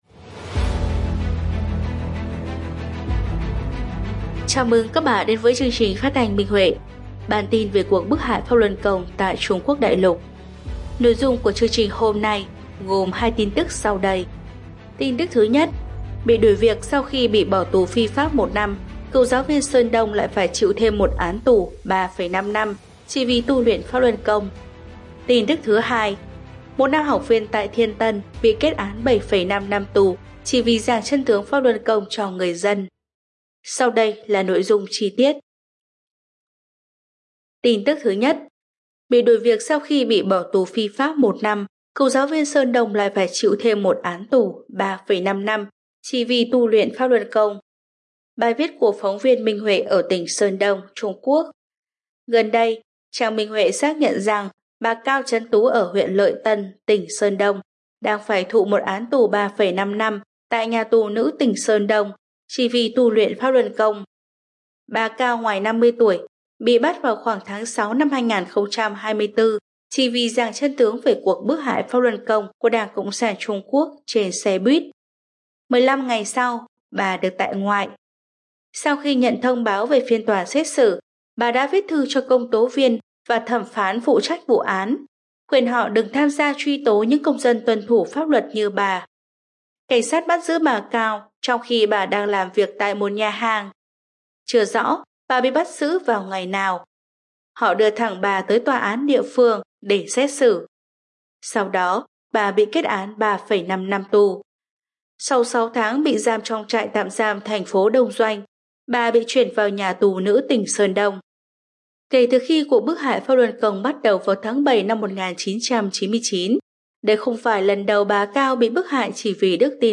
Chương trình phát thanh số 196: Tin tức Pháp Luân Đại Pháp tại Đại Lục – Ngày 8/4/2025